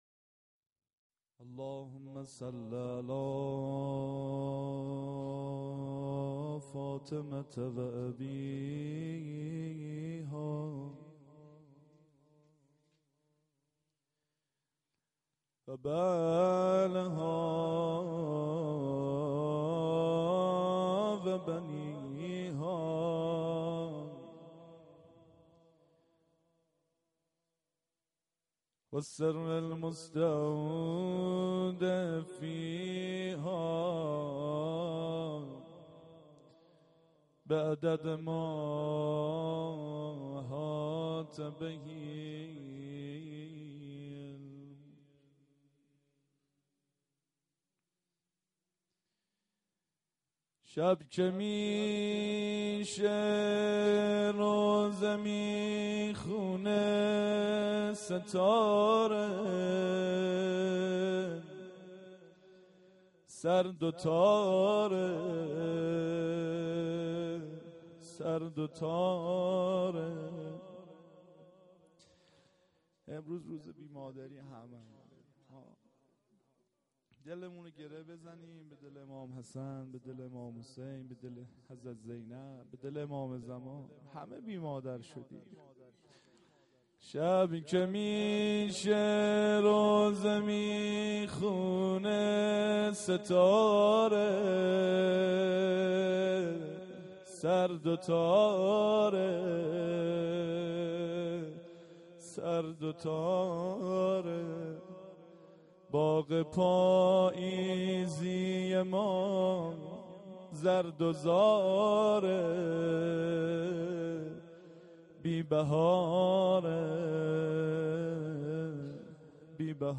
فاطمیه-دوم96-ظهر-شهادت-روضه.mp3